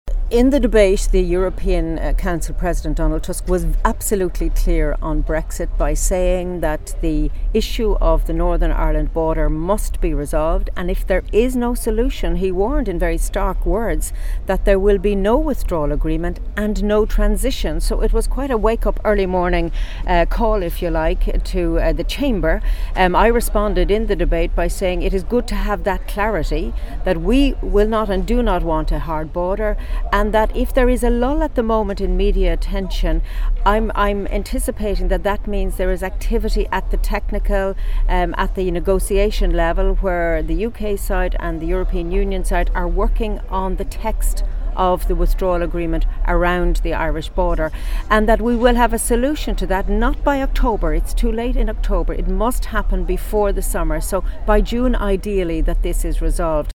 But MEP Mairead McGuinness and vice-president in the parliament, says this agreement needs to be reached by June and not by October as envisioned by London: